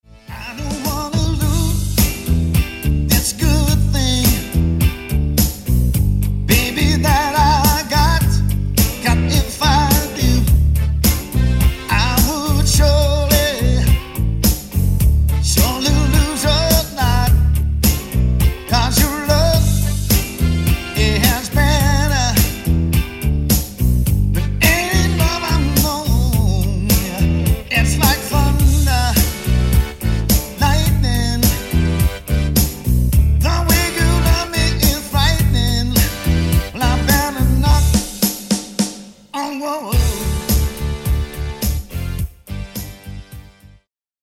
Singer saxophonist and entertainer.
Soul Music Samples